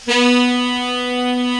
Index of /90_sSampleCDs/Giga Samples Collection/Sax/SAXIBAL LONG
TENOR SOFT B.wav